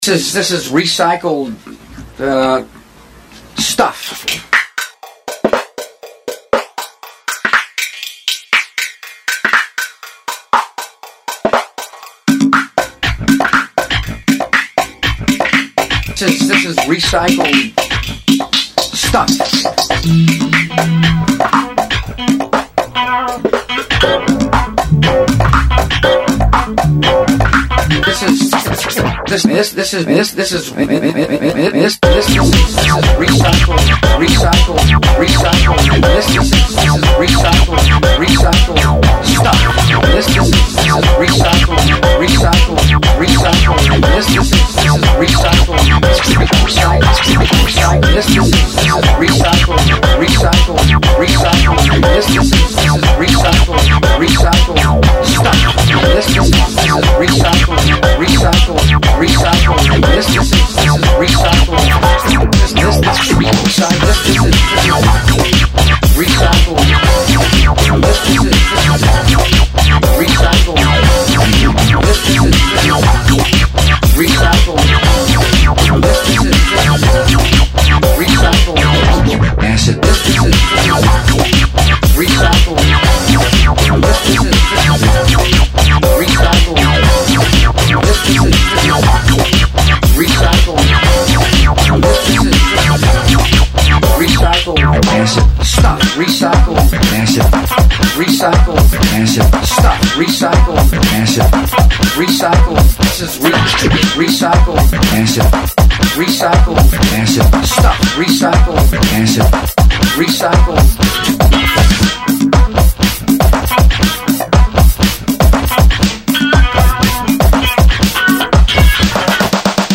Jam-dance mixed with a GWB scratch on the cut.